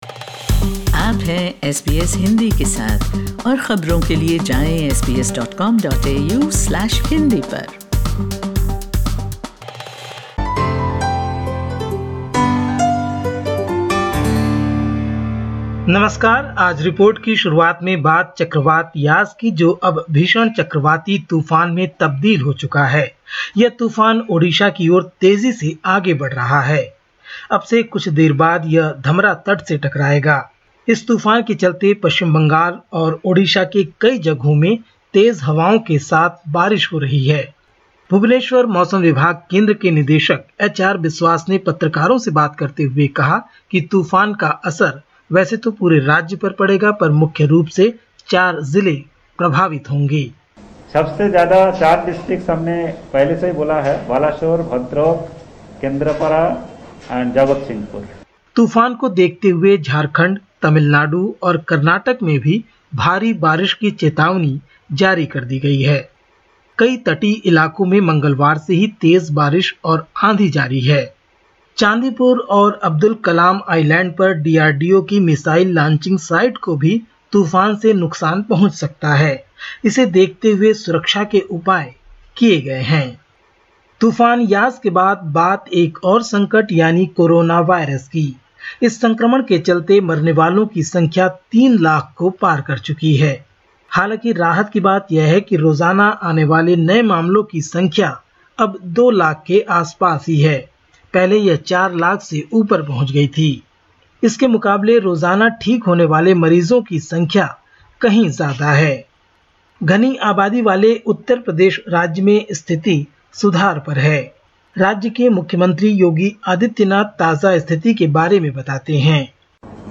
Catch the latest news from India in Hindi: 26/05/21